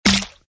SwordfisherHitsPlayer.ogg